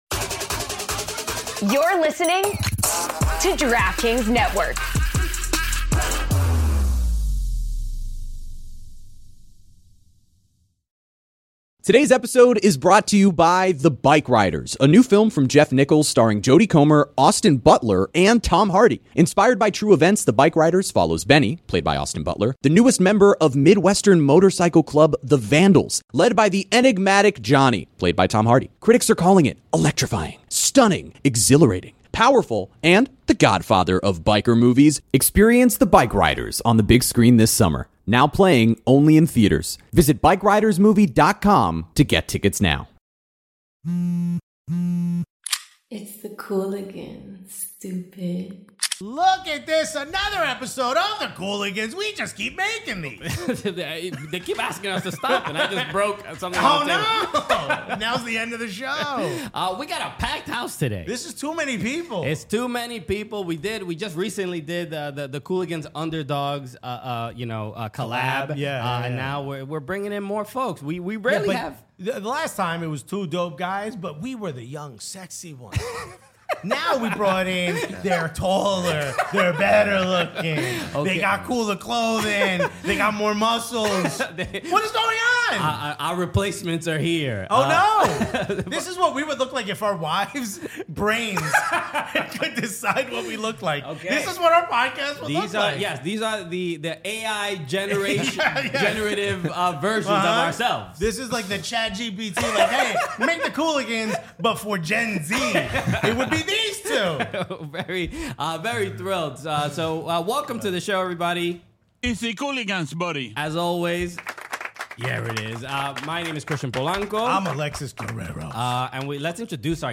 Podcast Stick to Football Gerrard: Liverpool, Salah Advice, Rangers Success & Alonso for Slot? | Stick to Football EP 123 Welcome back to Stick to Football, brought to you by ARNE. We’re delighted to be joined by Liverpool legend and one of our most requested guests, Steven Gerrard, as he sits down with the panel to reflect on his incredible career in the game. The conversation begins with the current situation at Liverpool.